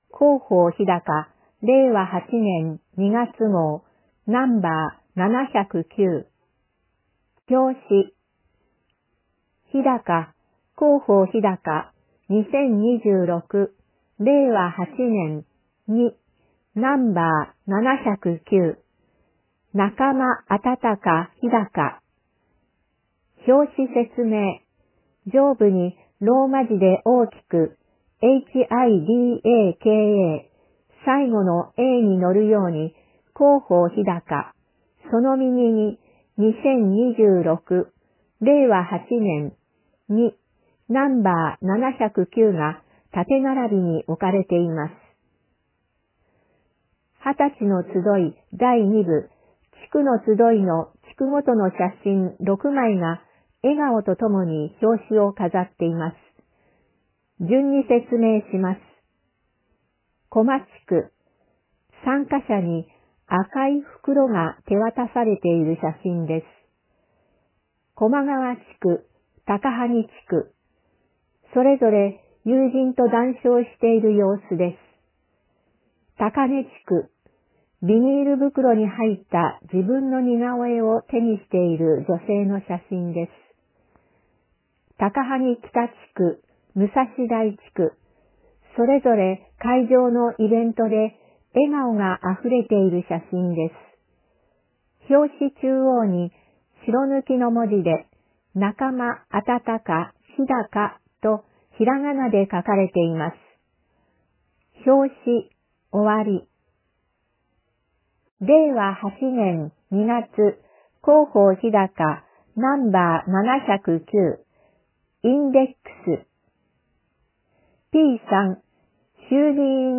朗読ボランティアグループ「日高もくせいの会」の皆さんのご協力により、「声の広報ひだか」を発行しています。